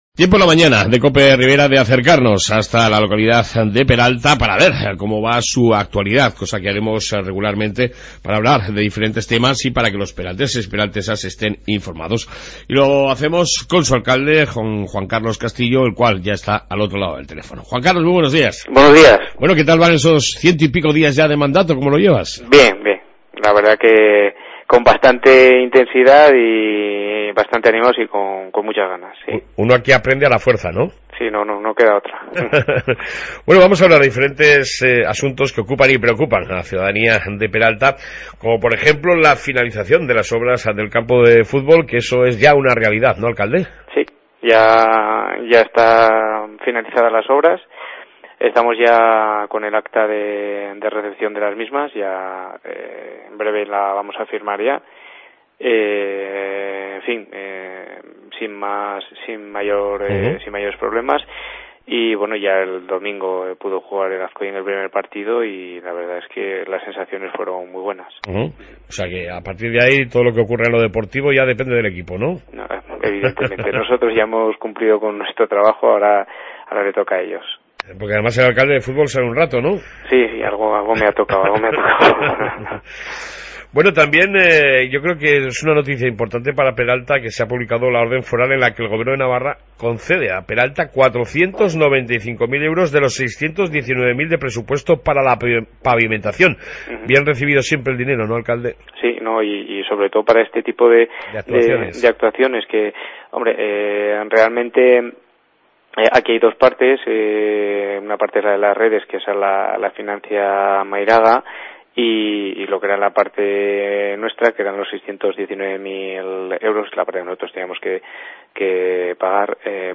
AUDIO: Amplia entrevista con el Alcalde de Peralta Juan Carlos Castillo